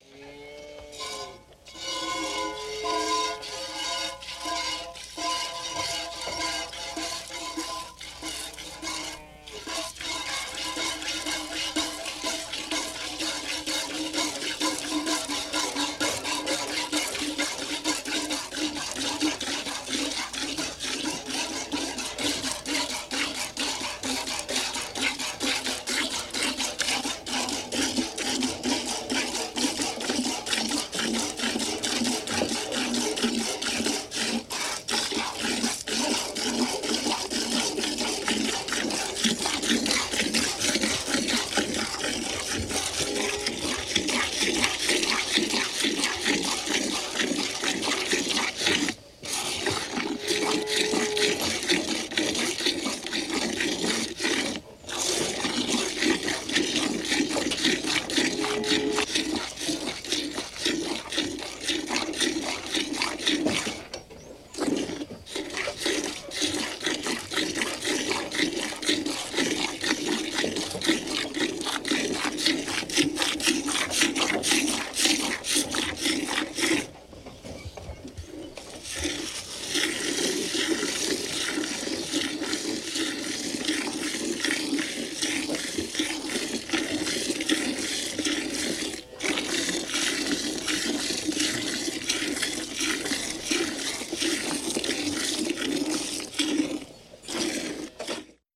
Звуки бидона
Звук доения коровы в бидон дояркой